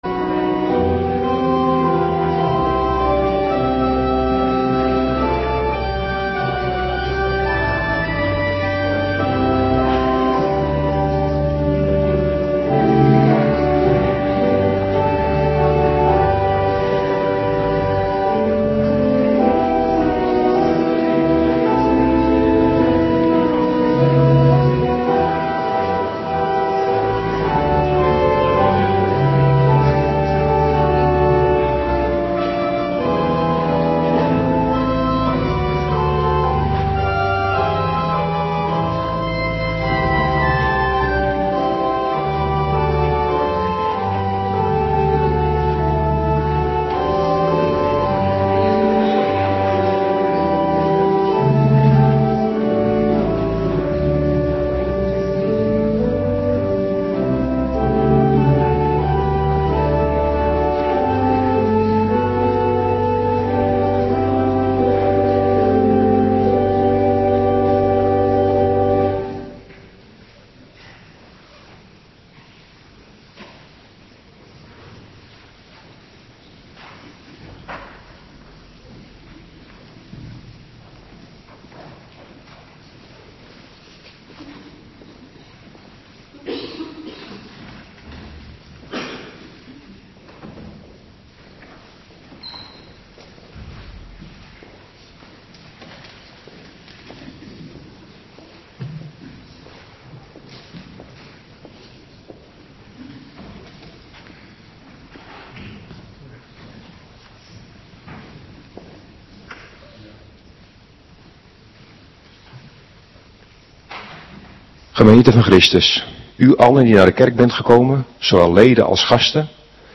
Avonddienst 22 februari 2026